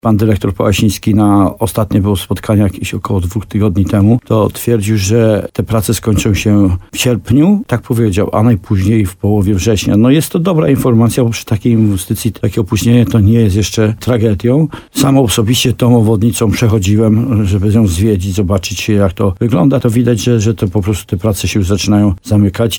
– Obwodnica Chełmca nie będzie gotowa na czas, czyli w lipcu – mówił w programie Słowo za słowo na antenie RDN Nowy Sącz wójt Bernard Stawiarski.